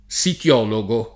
sitologo
vai all'elenco alfabetico delle voci ingrandisci il carattere 100% rimpicciolisci il carattere stampa invia tramite posta elettronica codividi su Facebook sitologo [ S it 0 lo g o ] o sitiologo [ S it L0 lo g o ] s. m.; pl. -gi